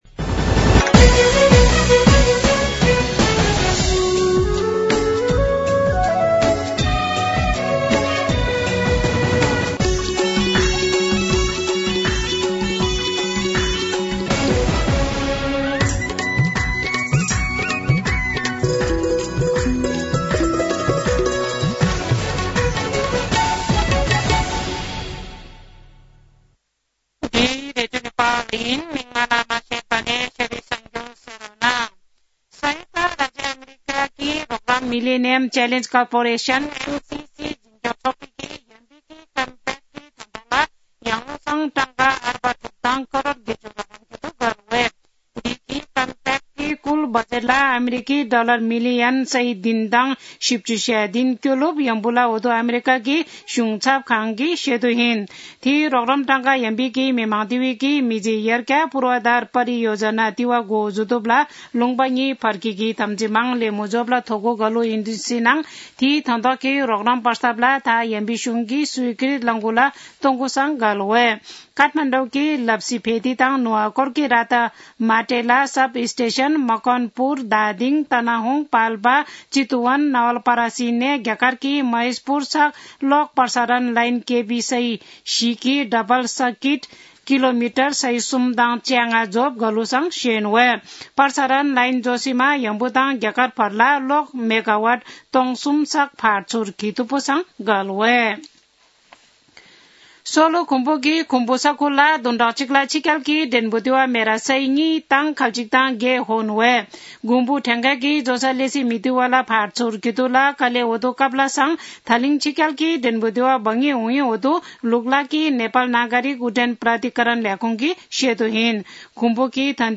शेर्पा भाषाको समाचार : २० पुष , २०८१
Sherpa-news.mp3